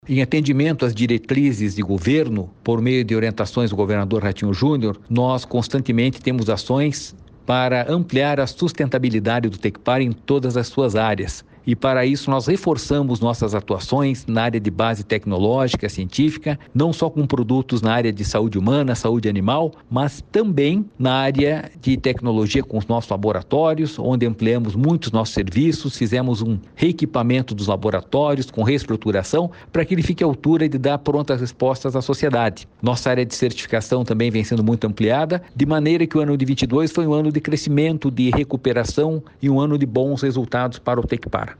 Sonora do diretor-presidente do Tecpar, Jorge Callado, sobre as ações do Instituto em 2022